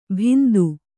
♪ bhindu